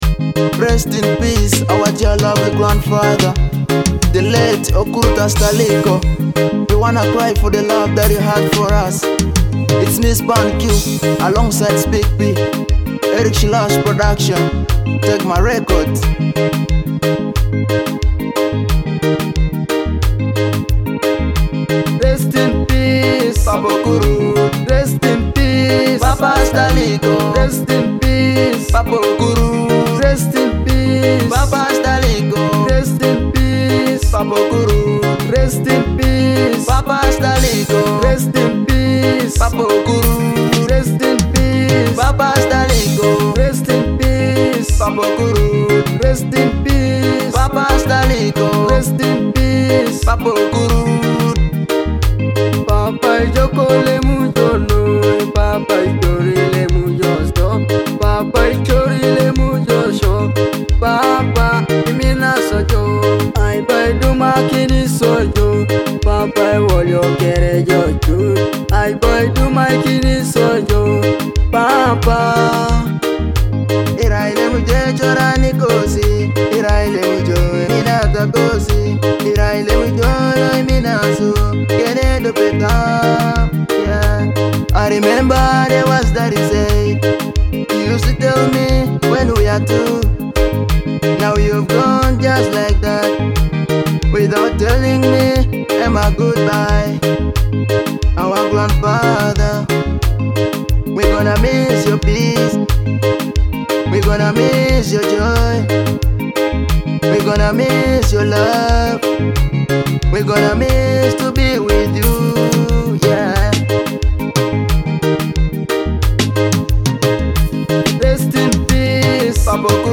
heartfelt tribute